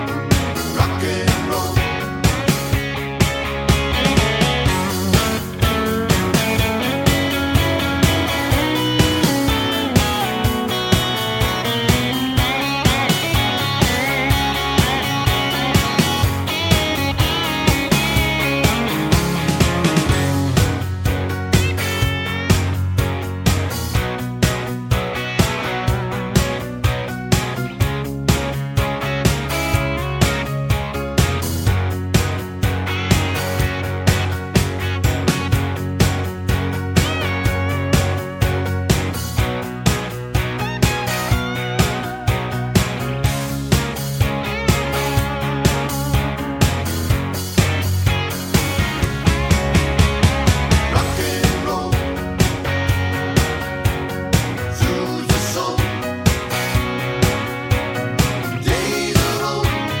no Backing Vocals Rock 'n' Roll 3:19 Buy £1.50